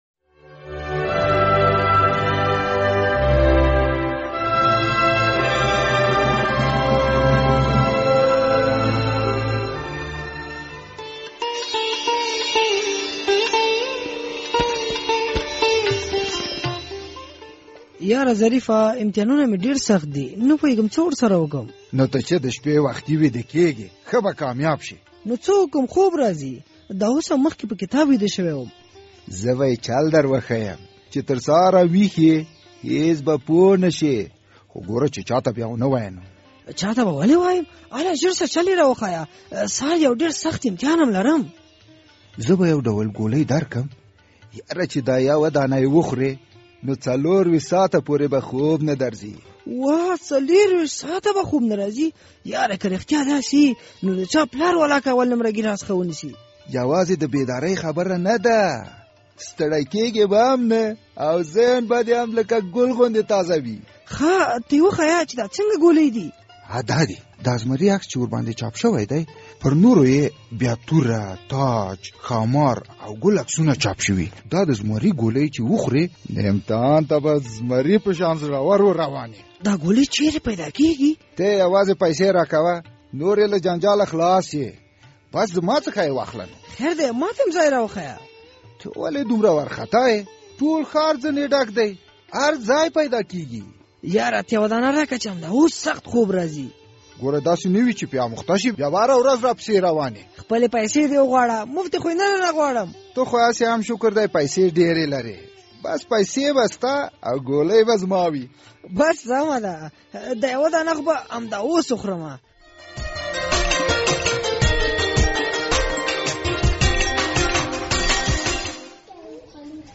د زهرو کاروان ډرامه